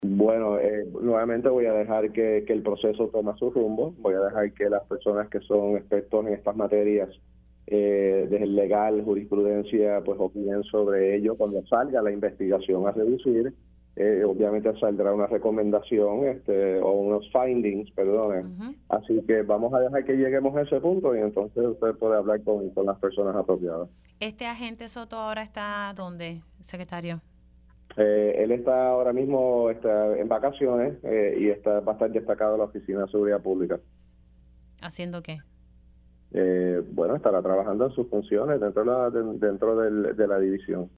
El secretario del Departamento de Seguridad Pública (DSP), General Arturo Garffer confirmó en Pega’os en la Mañana que le delegaron la investigación de la escolta de la secretaria de Justicia, Janet Parra al Negociado de la Policía de Puerto Rico (NPPR).